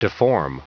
Prononciation du mot deform en anglais (fichier audio)
Prononciation du mot : deform